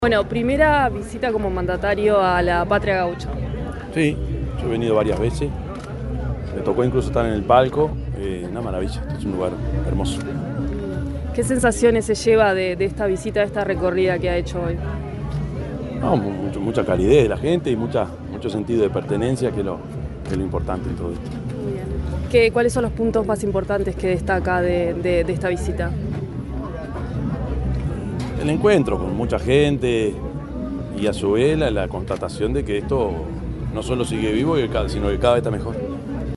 Declaraciones del presidente Yamandú Orsi